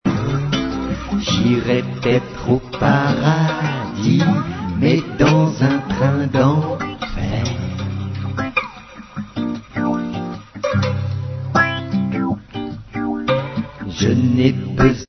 chanson humoristique